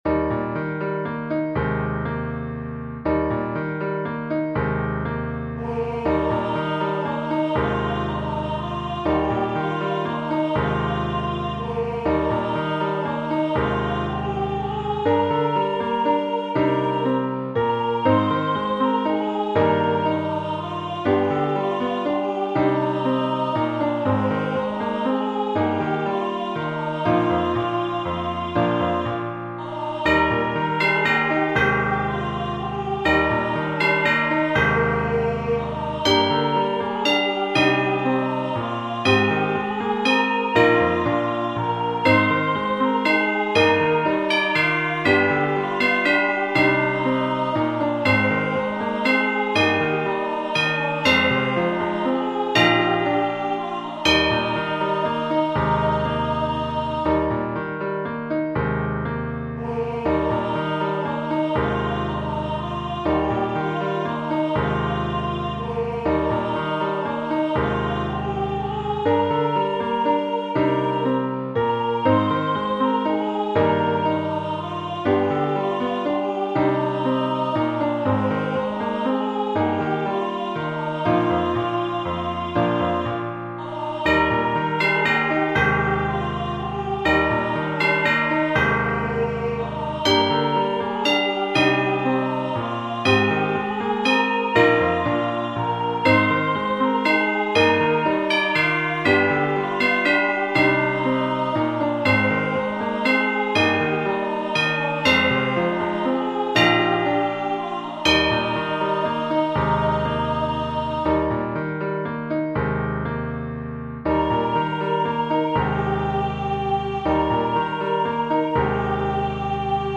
2 part choir
Hand Bells/Hand Chimes
This original Christmas song was written with children’s choirs in mind, but can be used by anyone! The words, melody, and descant are sweet and simple, and accompanied by piano and optional hand bells.